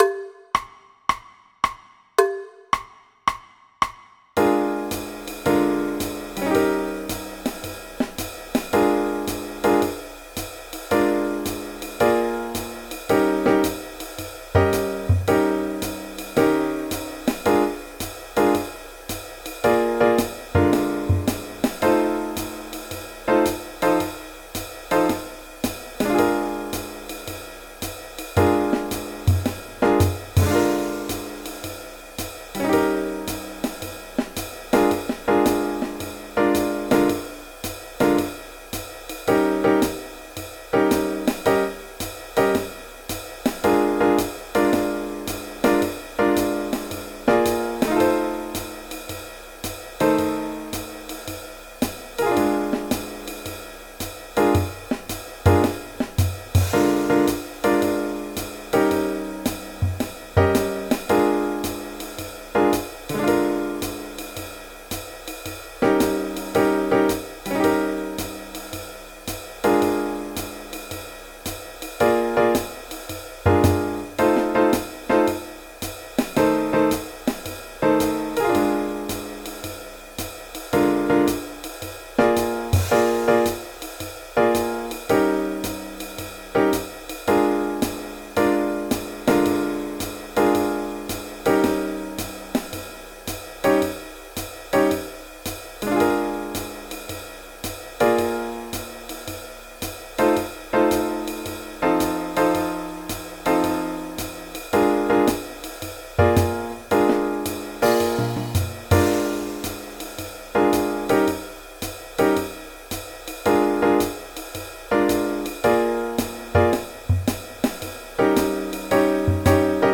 Backing Track (For Bass)